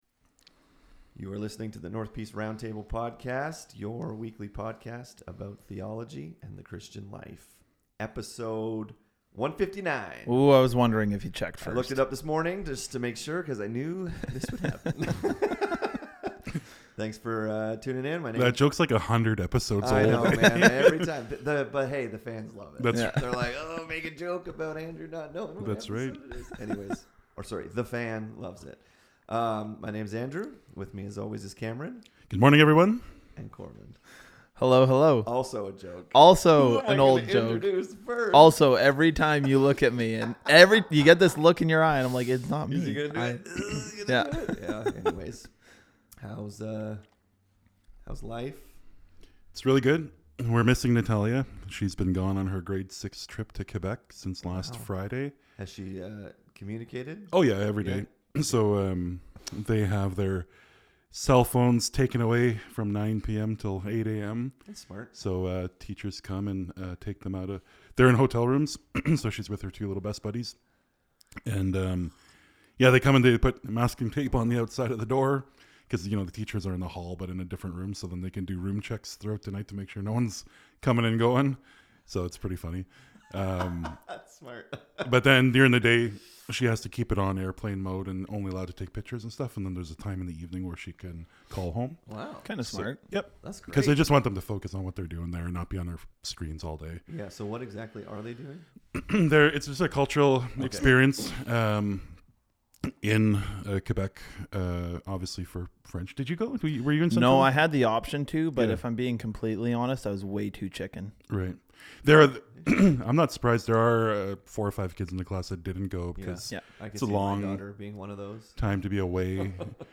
In this episode the guys talk about a Christian view of grief. How do we walk with people through pain and suffering?